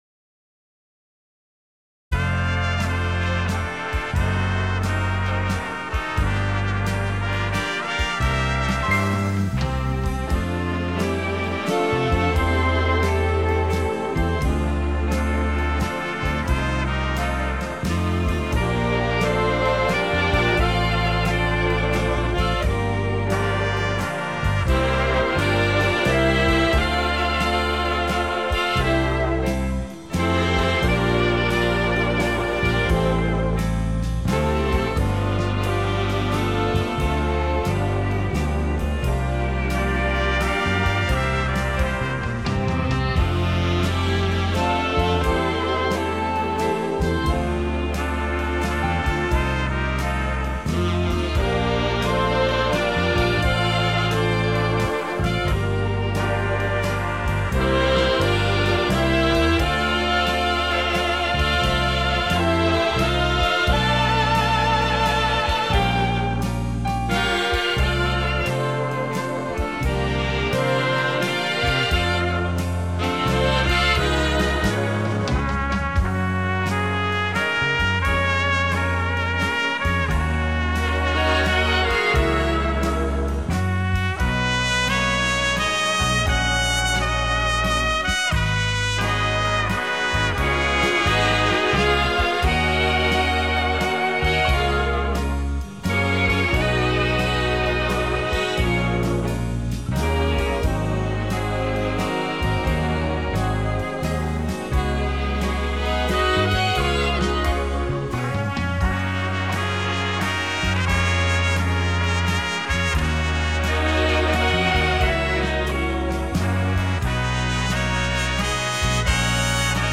Мое ухо слышит, что солирует труба.
Основная платформа- дуэт саксофонов и небольшая тема-две трубы .
Речь идёт не о начале, а солировании (где-то с 1 мин 15 сек и с 1 мин 49 сек).